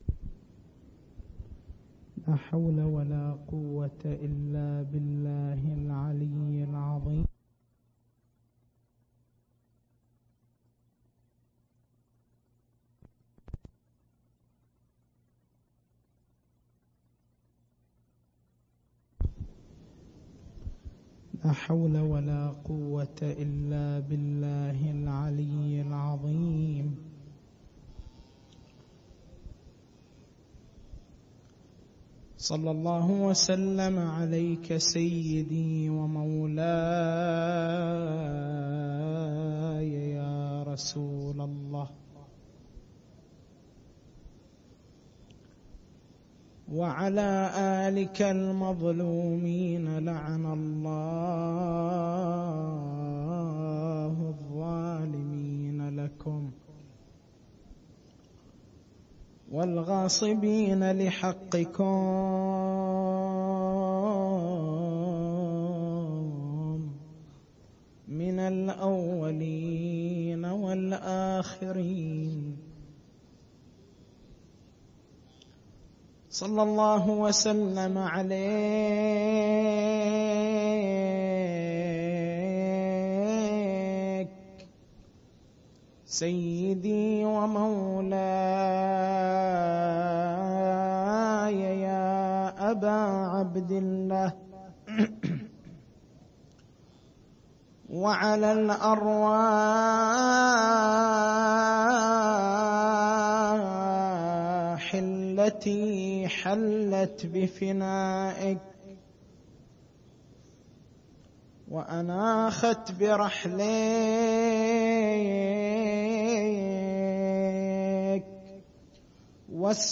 مكتبة المحاضرات > شهر رمضان المبارك